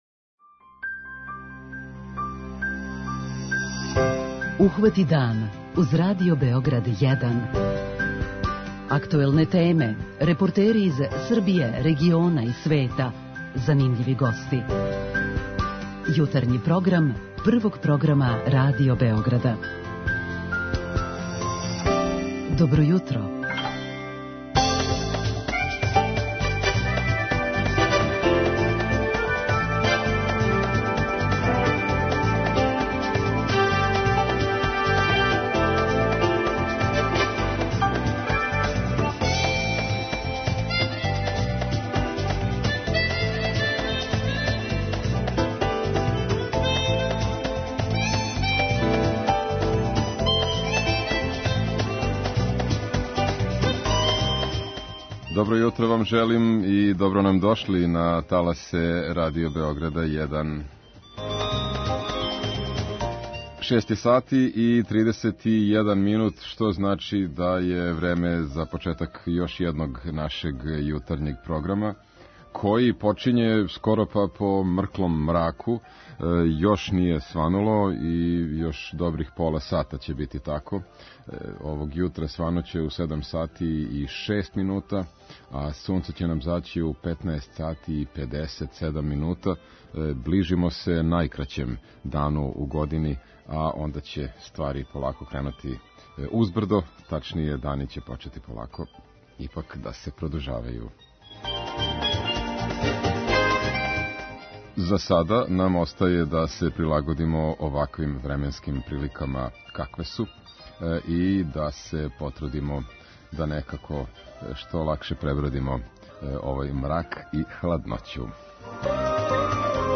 С југа, из околине Врања, стиже нам репортажа о младим људима који су се организовали и волонтерски обновили плато испред Месне заједнице у једном селу.